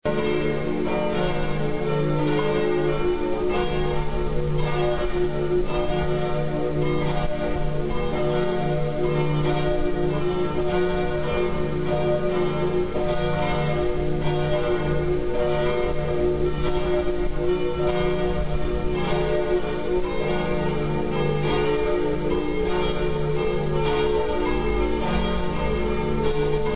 church
bells.
bell-joc.mp3